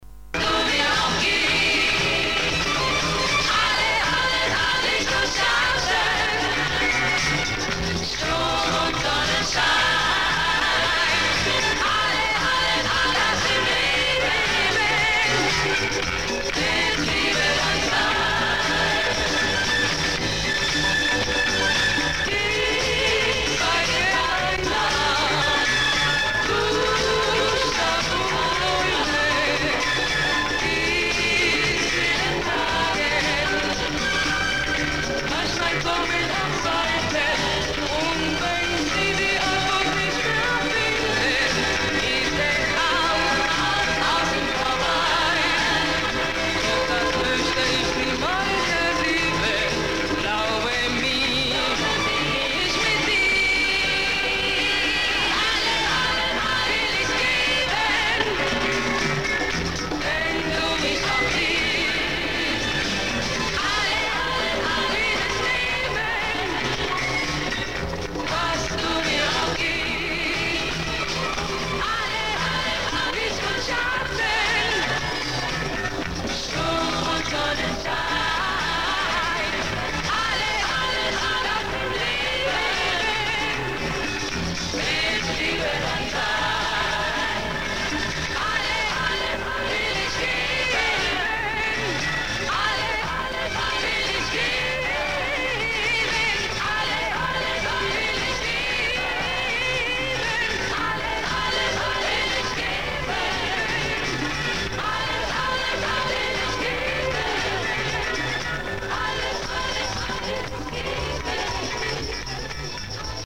Запись с телевидения СССР начало 80-х. Скорее всего из соцлагеря,
Голос меццо-сопрано?
unknown-german-song.mp3